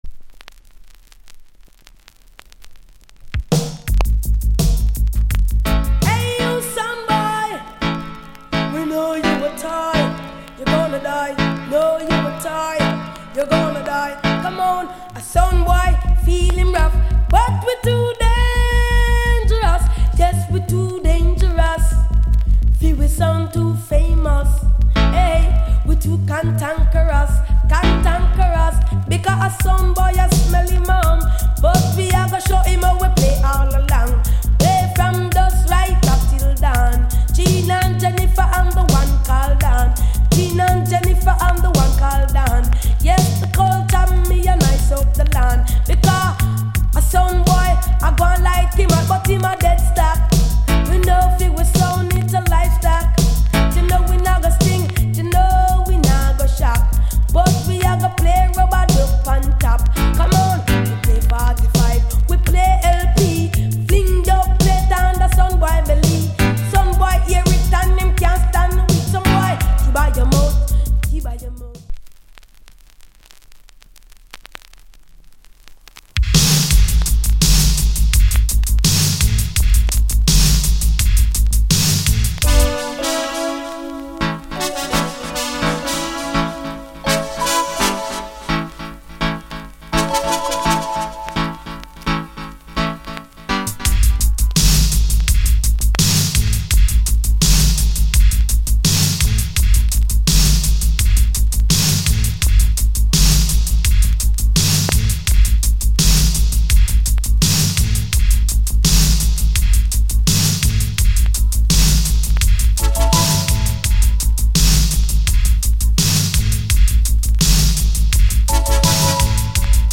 ガキ声Sound Tune!!